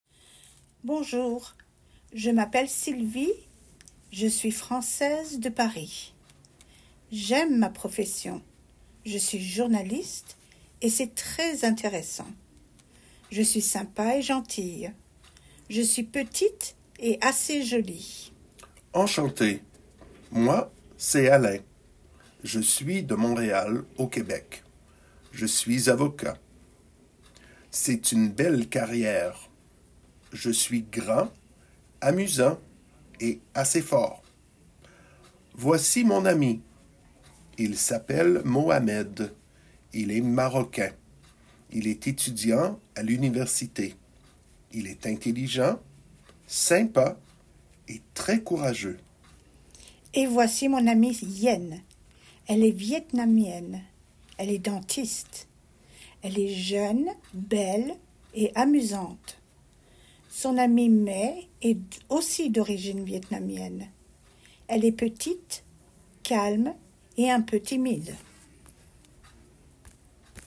Dialogue – Un 2